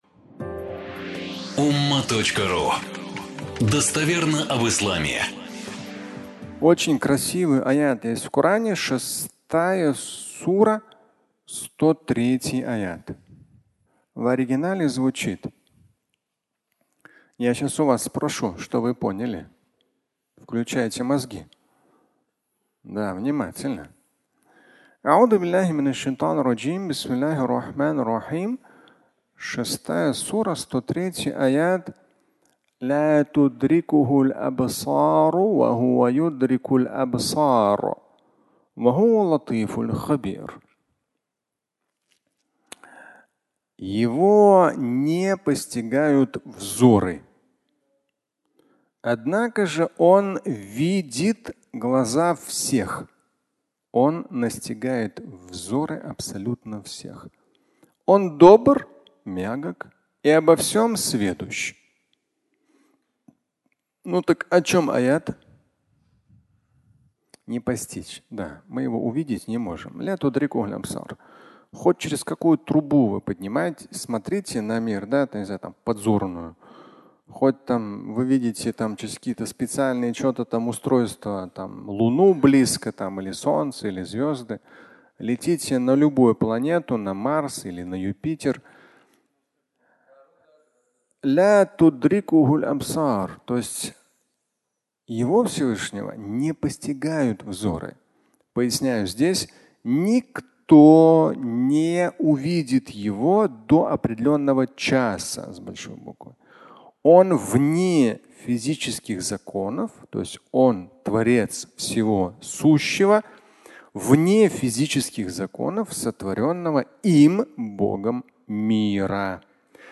А Он видит меня? (аудиолекция)